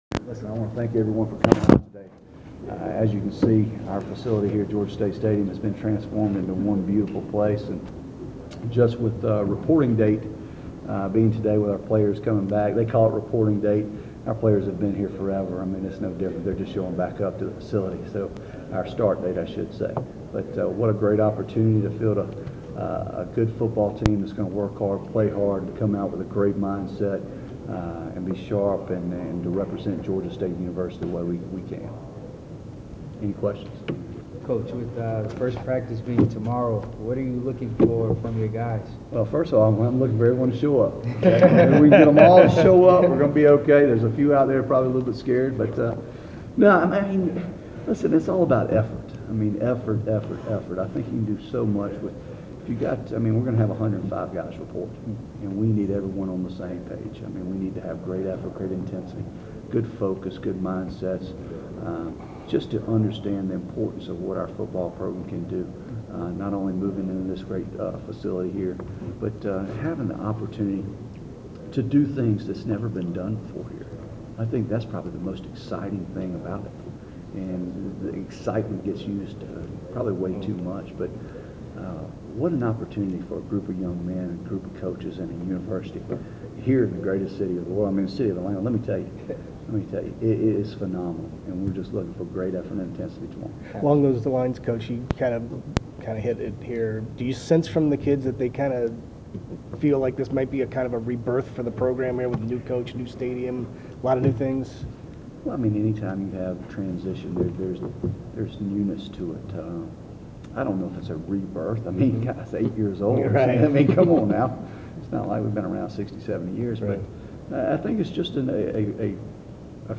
media day presser